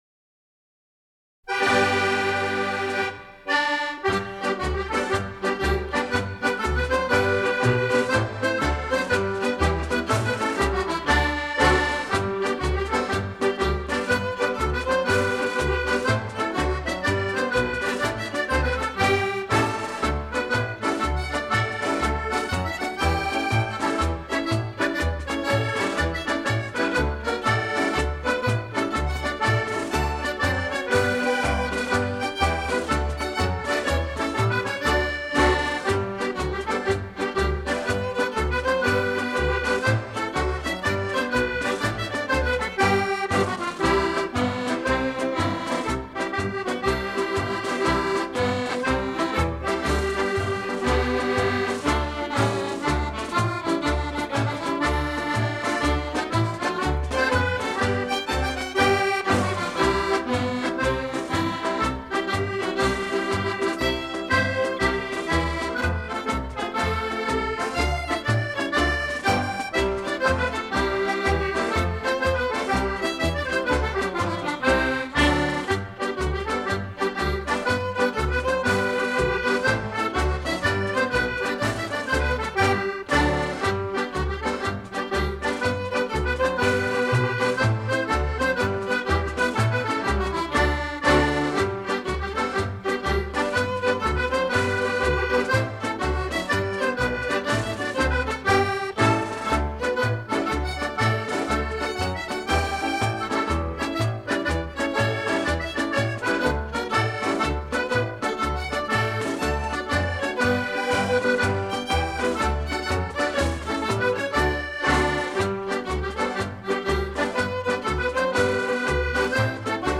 Genre: Contemporary Folk.